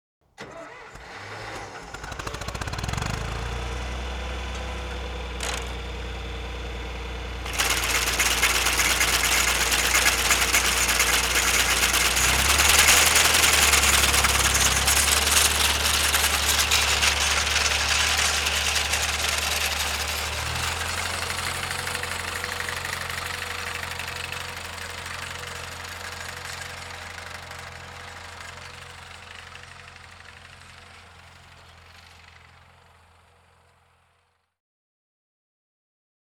Tractor Drive With Cutter Bar Sound
transport
Tractor Drive With Cutter Bar